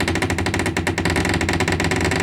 جلوه های صوتی
دانلود صدای در 8 از ساعد نیوز با لینک مستقیم و کیفیت بالا
برچسب: دانلود آهنگ های افکت صوتی اشیاء دانلود آلبوم صدای باز و بسته شدن درب از افکت صوتی اشیاء